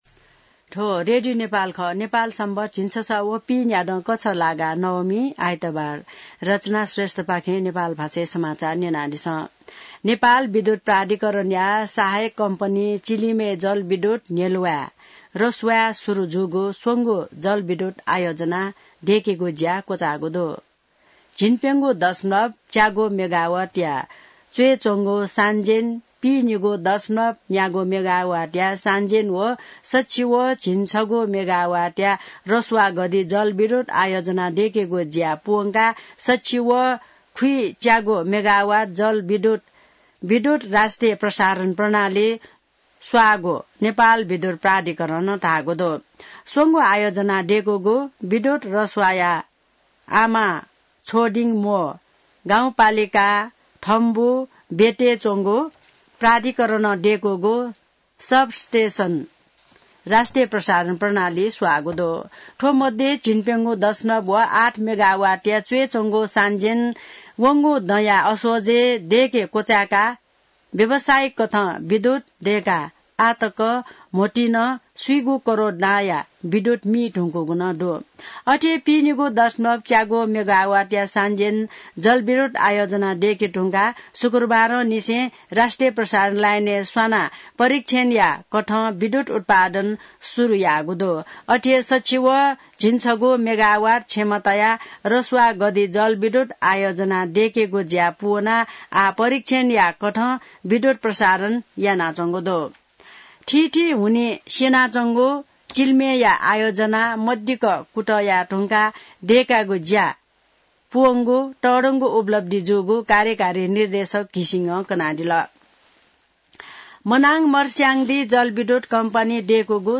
नेपाल भाषामा समाचार : १० मंसिर , २०८१